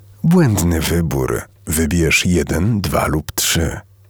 Głos o niskiej intonacji, z subtelnym ciepłem, które wprowadza słuchacza w spokojny, intymny nastrój. Jego niska tonacja nadaje każdemu słowu charakteru, a głębia brzmienia sprawia, że jest przyjemny w odbiorze.
Centralka telefoniczna: realizacja dla W&H Hetmaniok